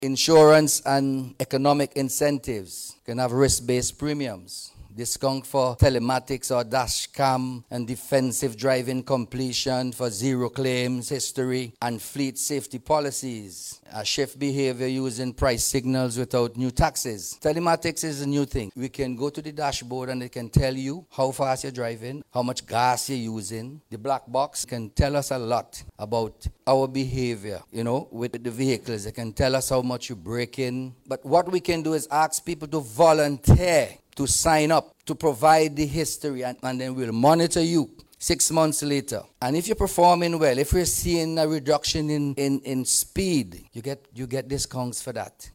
Speaking at the recent Jaric St Vincent Ltd Road Safety Conference